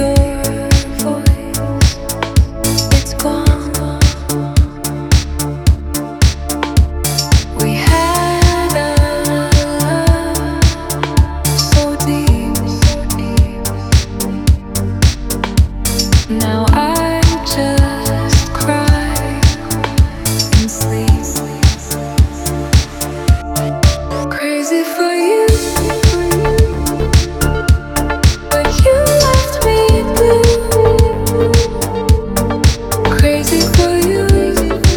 Жанр: Танцевальные / Электроника
Electronic, Dance